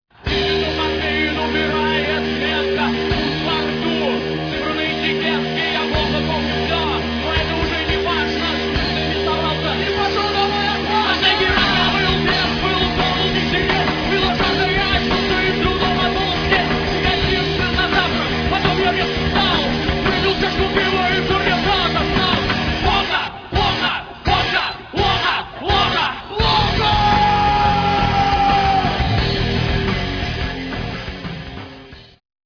Питерский рок-фестиваль (1997)
фрагмент песни (32 сек.)